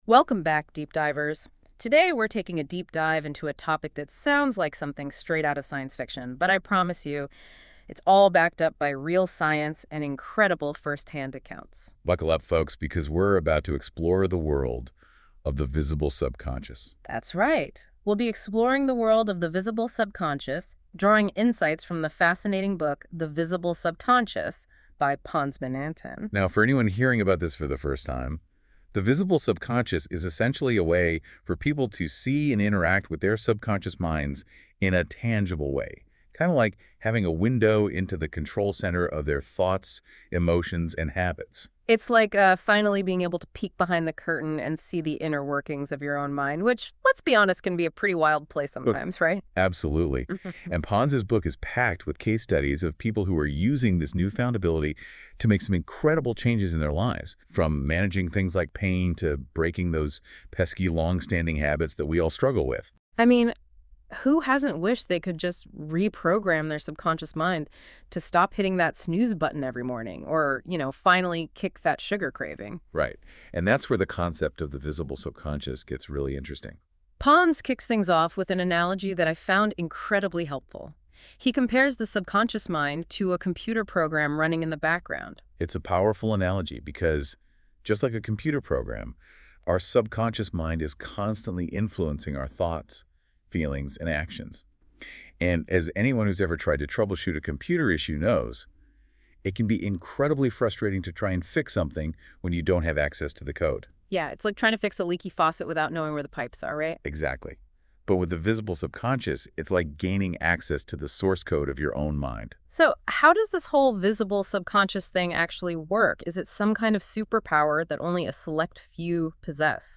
Deep Dive : A Conversation on The Visible Subconscious Book